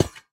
sounds / block / trial_spawner / step1.ogg